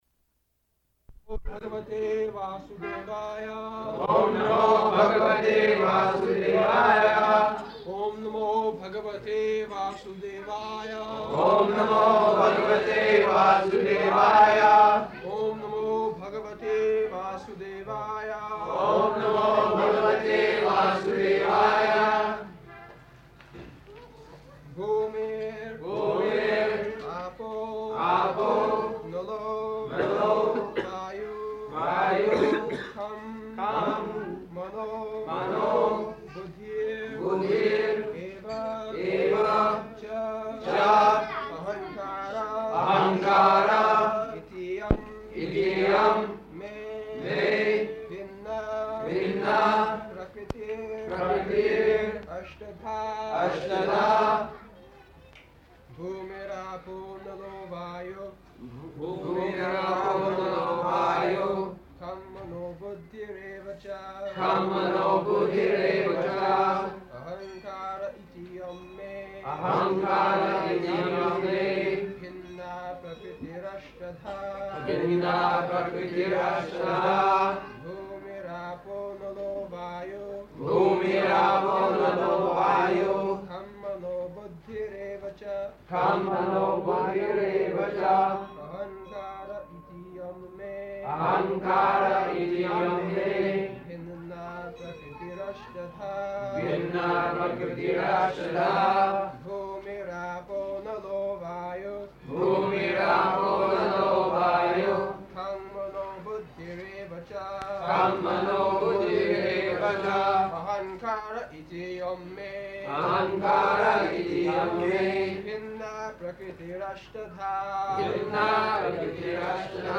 February 19th 1974 Location: Bombay Audio file